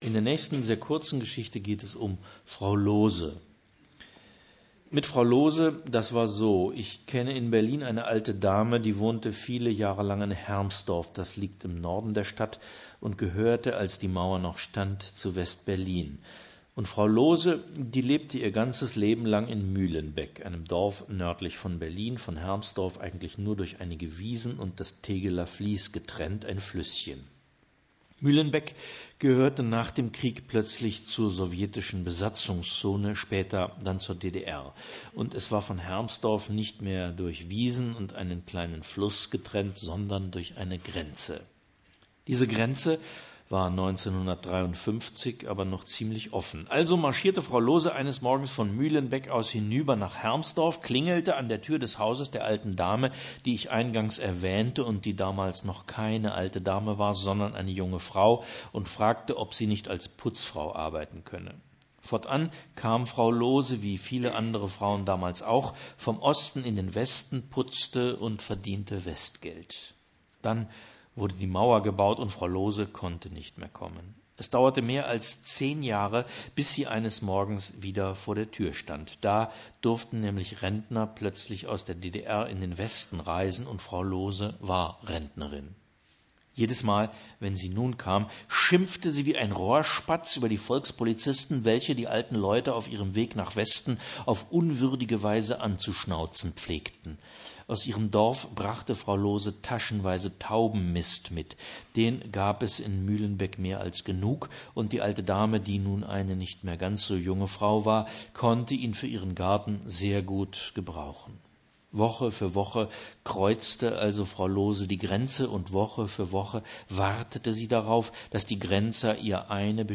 Axel Hacke (Sprecher)